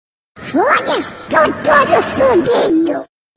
pato donald 2 Meme Sound Effect